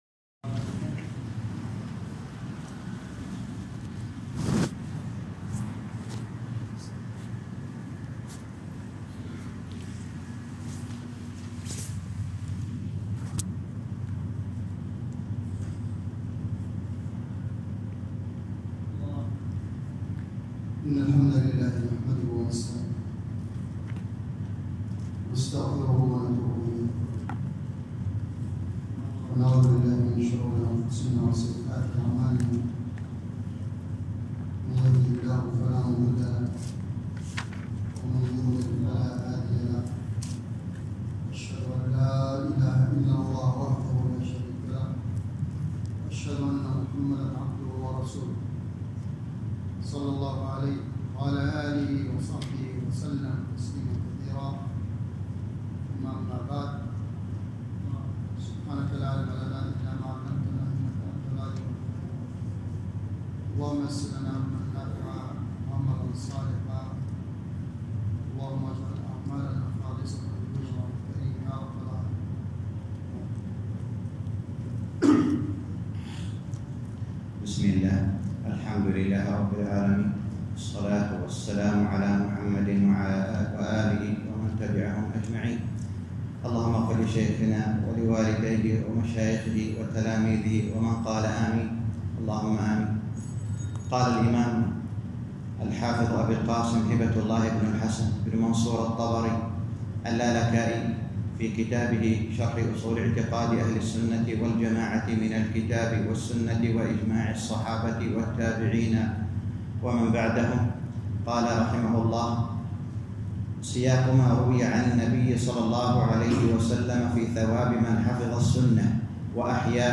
شرح أصول اعتقاد اهل السنة والجماعة الامام الحافظ اللالكائي (الشرح الجديد) الدرس الثامن